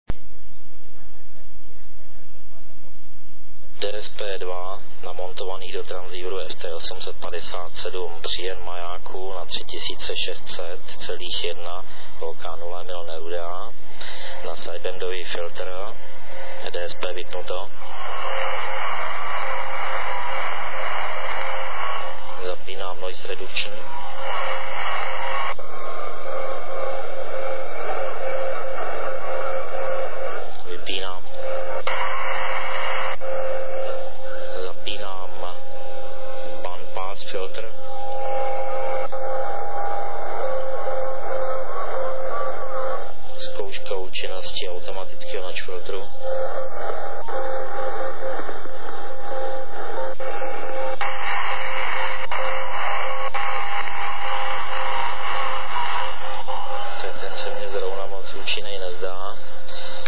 Testy probíhaly v době kolem 15. hodiny a byl poslouchán zašumělý QRP maják OK0EN na kmitočtu 3600.10 KHz.